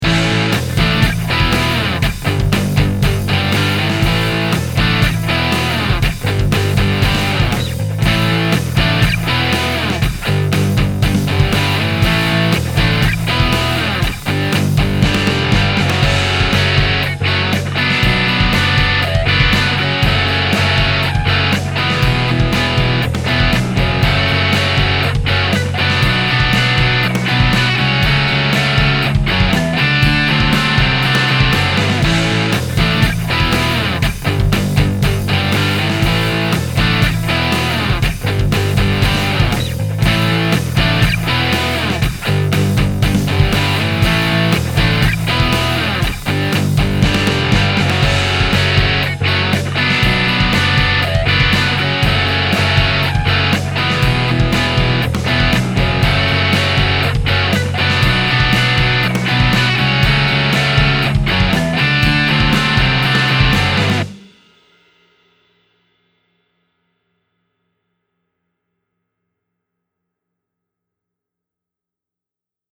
Before (single guitar track):
1-single_v3.mp3